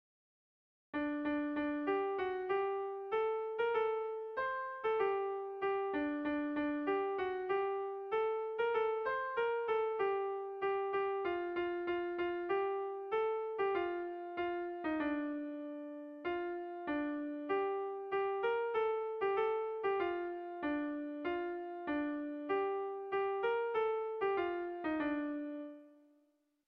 Haurrentzakoa
Seiko txikia (hg) / Hiru puntuko txikia (ip)
AAB